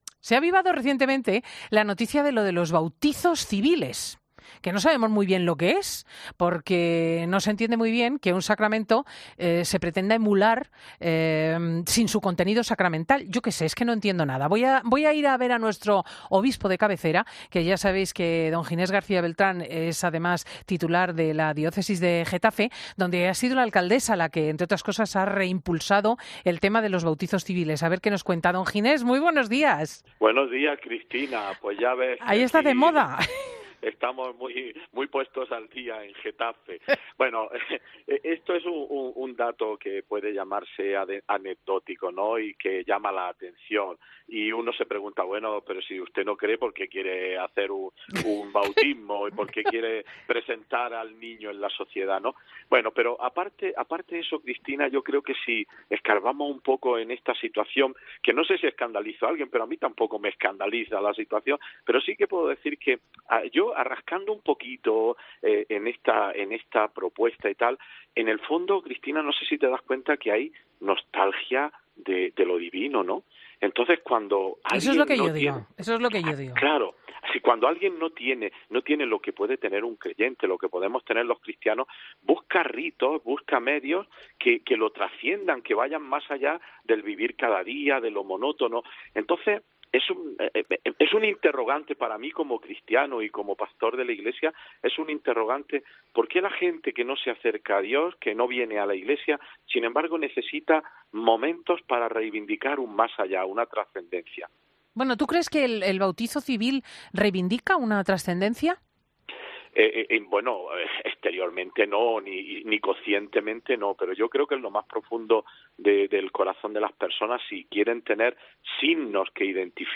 Monseñor Ginés García Beltrán habla en Fin de semana sobre la iniciativa del Auyuntamiento de Getafe de celebrar bautizos civiles